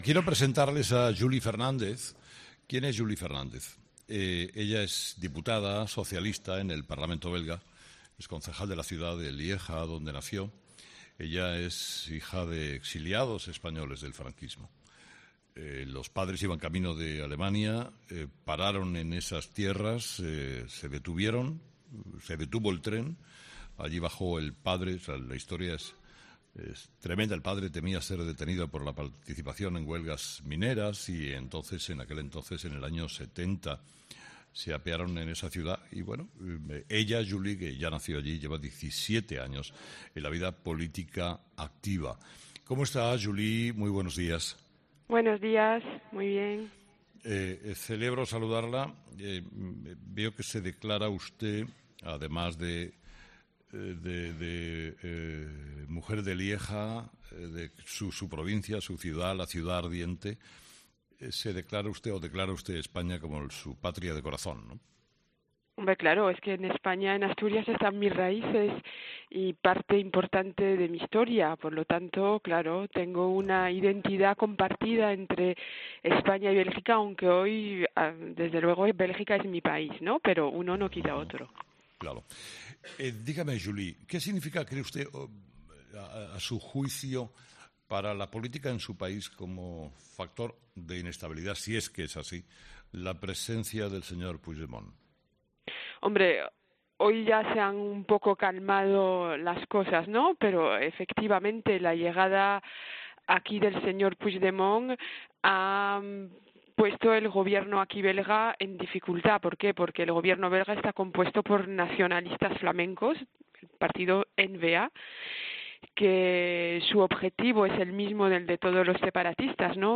Julie Fernández, diputada socialista en el Parlamento belga